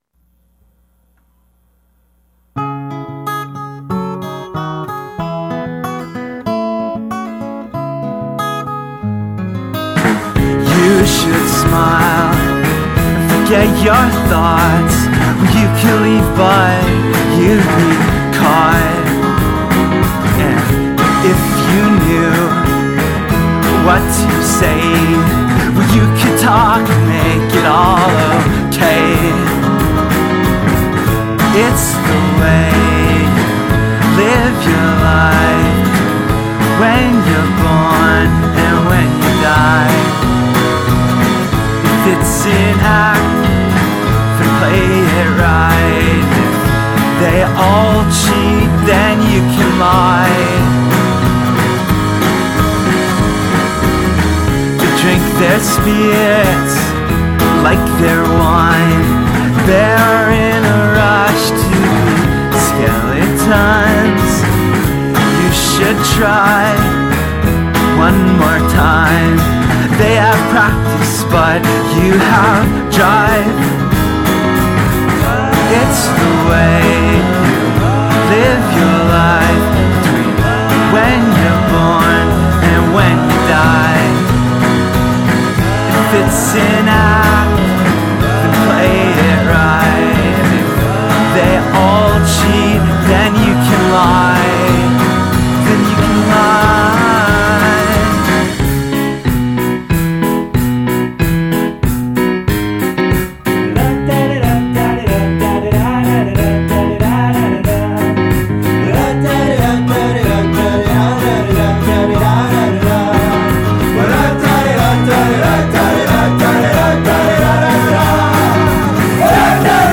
Vocals + Acoustic Guitar
Drums
Electric Guitar
Bass